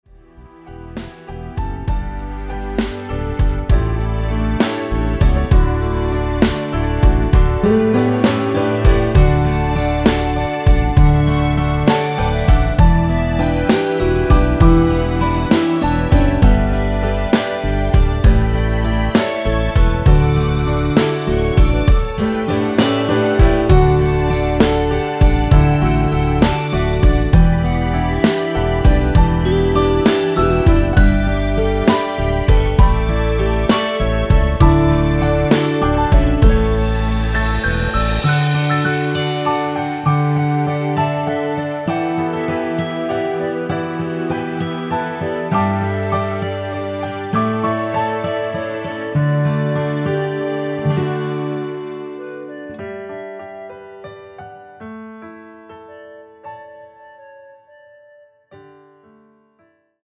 고음 부분의 부담없이 부르실수 있게 편곡 하였습니다.
원키에서(-6)내린 (Easy Ver.) 멜로디 포함된 MR 입니다.
D
앞부분30초, 뒷부분30초씩 편집해서 올려 드리고 있습니다.
중간에 음이 끈어지고 다시 나오는 이유는